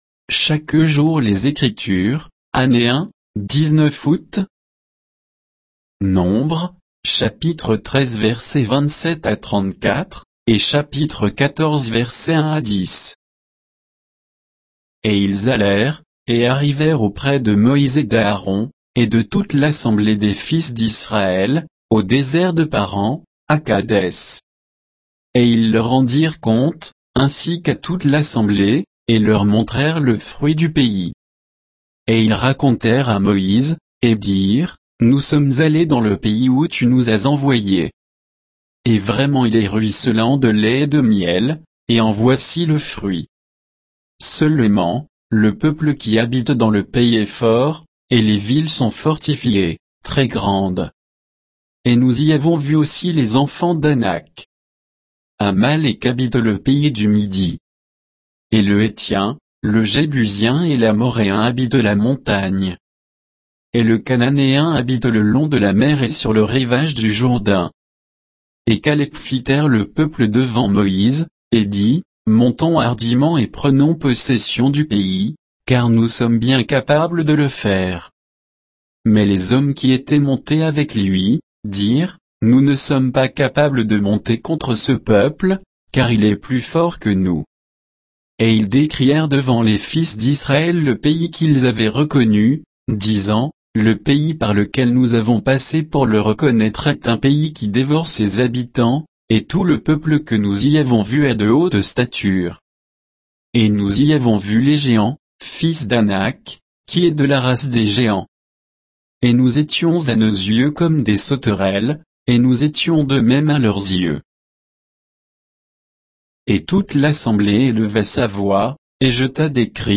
Méditation quoditienne de Chaque jour les Écritures sur Nombres 13, 27 à 14, 10